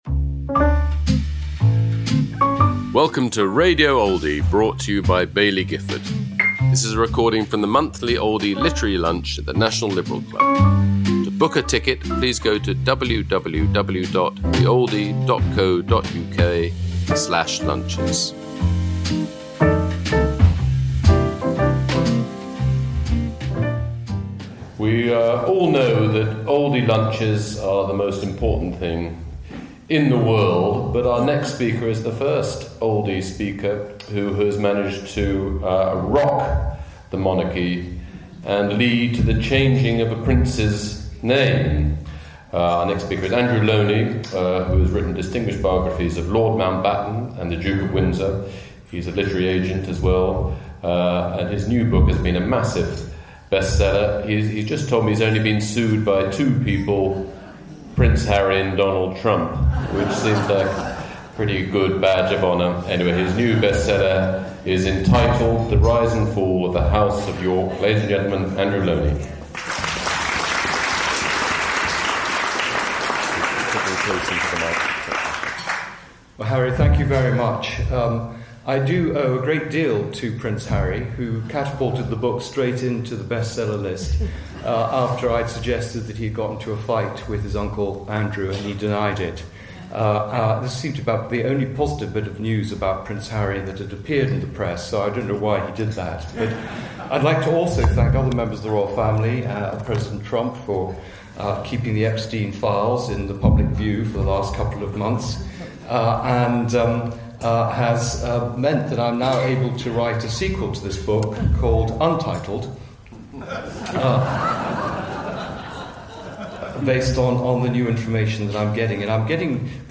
Andrew Lownie speaking about his new book, Entitled: Prince Andrew, at the Oldie Literary Lunch, held at London’s National Liberal Club, on November 25th 2025.